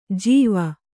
♪ jīva